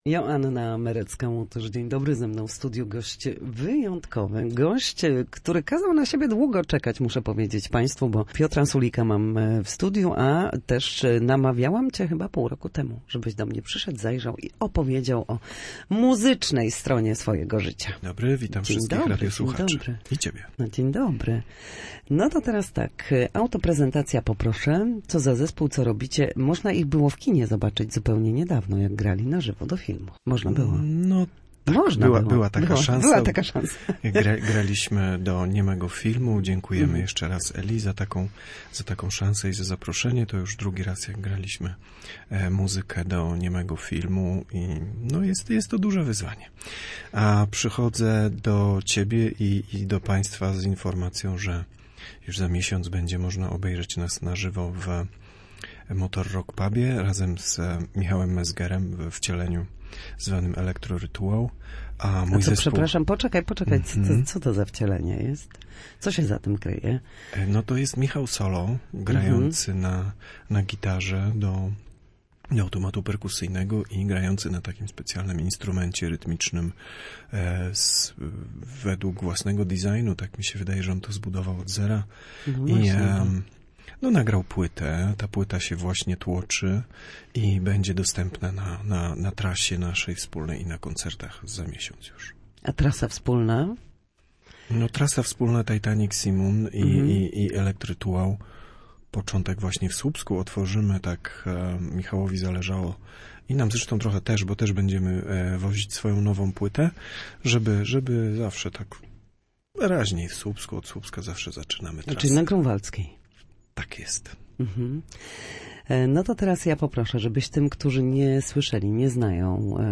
Na naszej antenie opowiada o nowej płycie, przyjemności z grania oraz nadchodzącej trasie koncertowej.